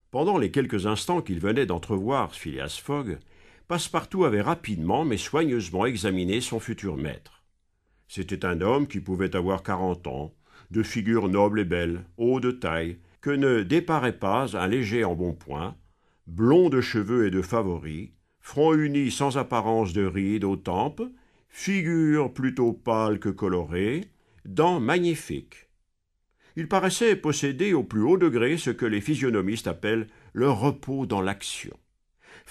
Click for an excerpt - Le tour du monde en 80 jours de Jules Verne